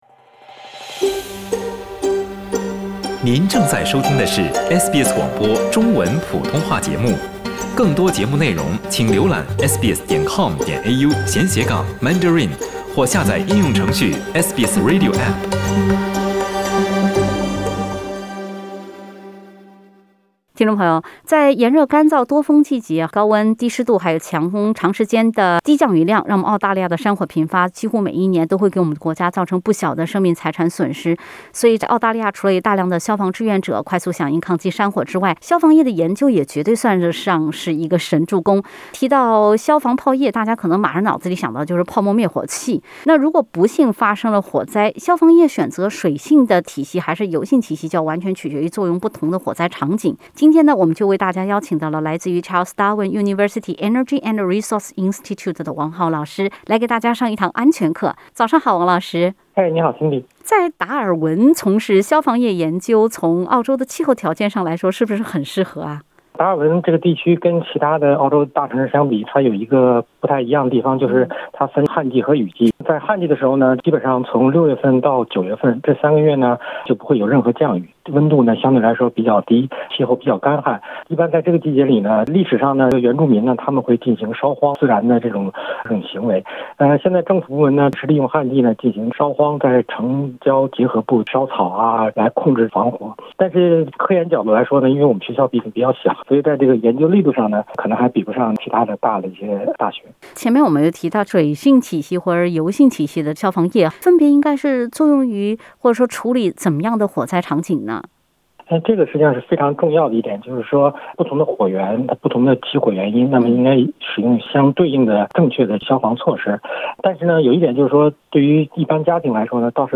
家用灭火器的正确使用方法其实有个简单易学的口诀。（点击封面图片，收听完整采访）